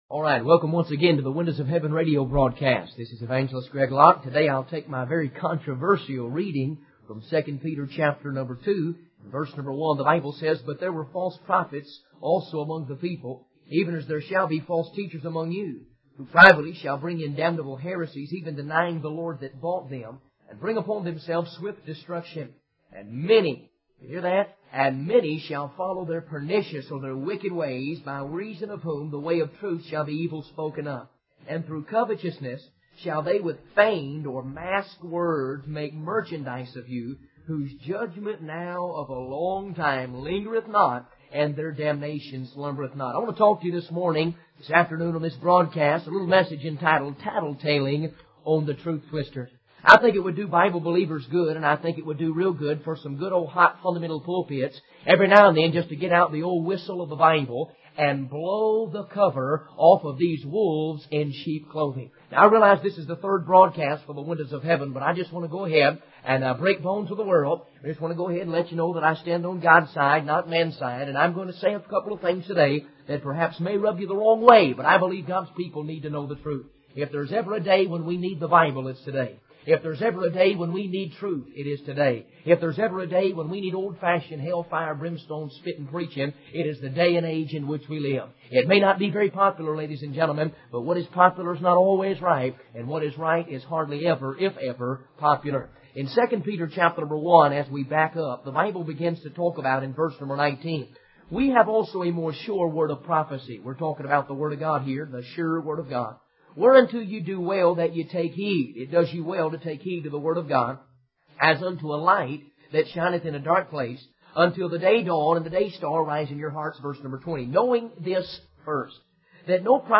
In this sermon, the preacher warns about false prophets who deceive people with lies and false teachings. He references biblical examples such as the angels who sinned, the flood in Noah's time, and the destruction of Sodom and Gomorrah.